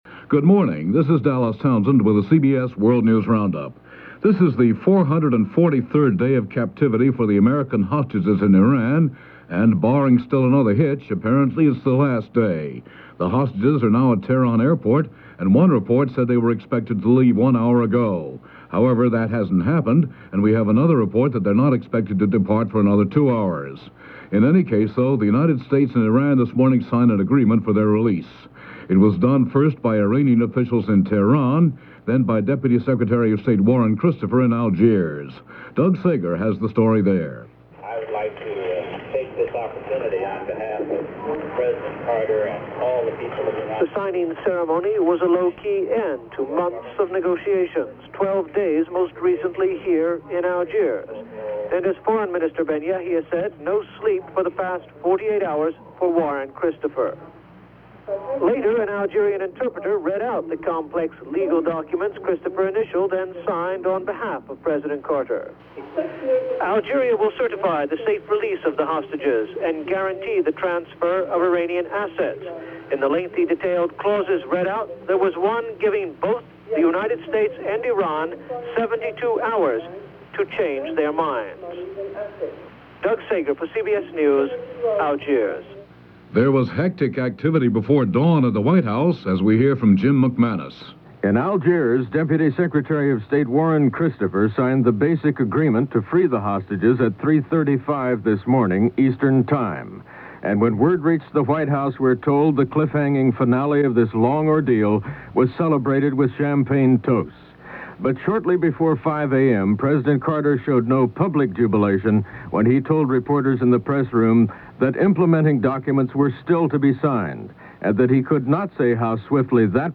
January 19, 1981 – CBS World News Roundup